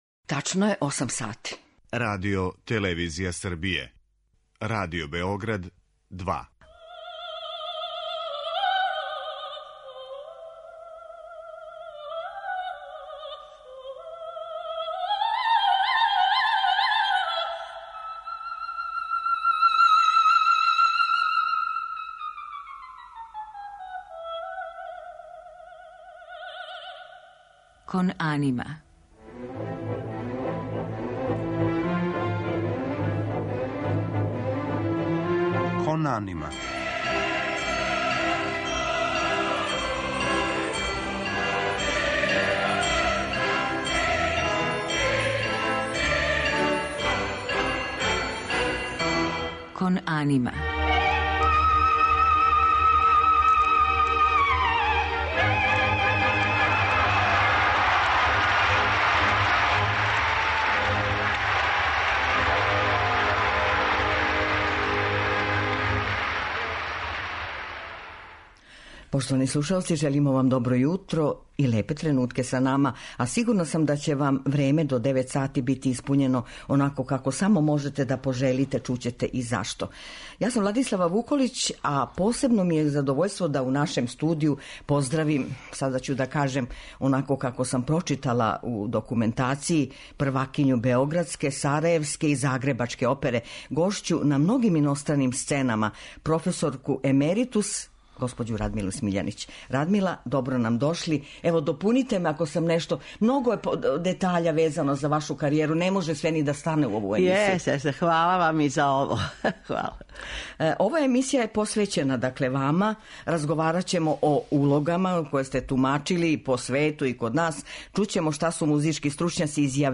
Гошћа данашње емисије, професор емеритус Радмила Смиљанић, дугогодишња првакиња опера у Београду, Сарајеву и Загребу, говориће о својим улогама, гостовањима, нашим и страним колегама са којима је сарађивала...
У музичком делу биће емитоване арије италијанских мајстора Пучинија и Вердија, као и из опера Рихарда Штрауса и Беджиха Сметане.
Посебно је занимљив дует из „Трубадура" са познатим баритоном Николом Мијајловићем, њеним сином.